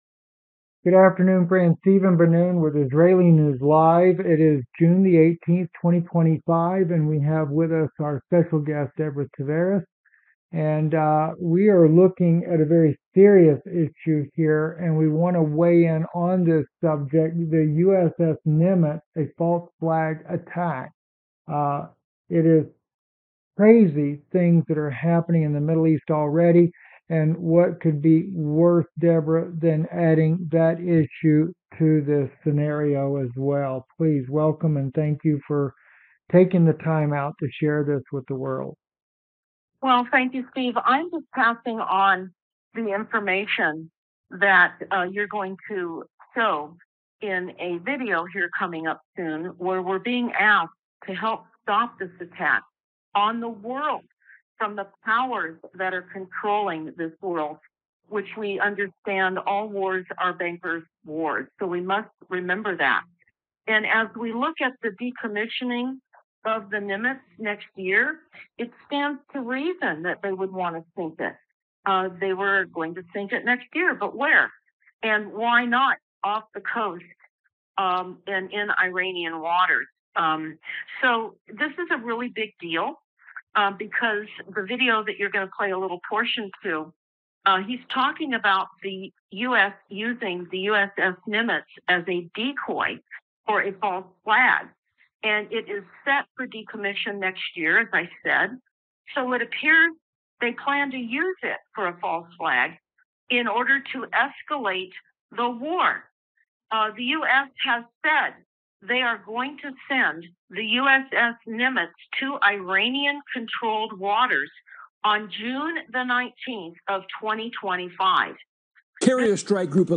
In a startling new interview